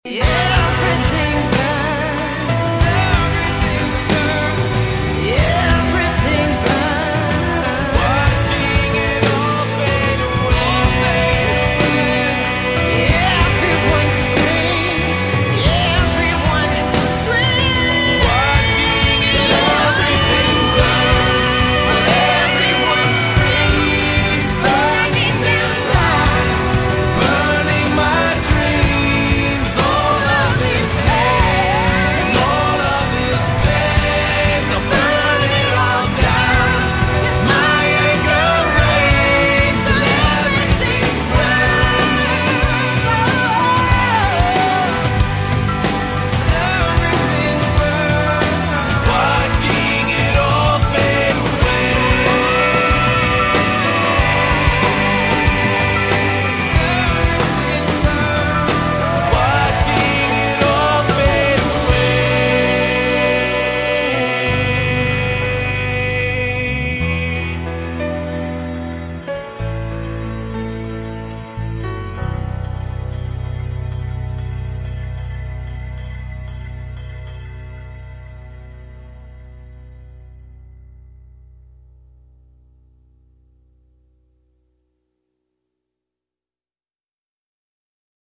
• Качество: 128, Stereo
громкие
грустные
Alternative Rock
Pop Rock
мужской и женский вокал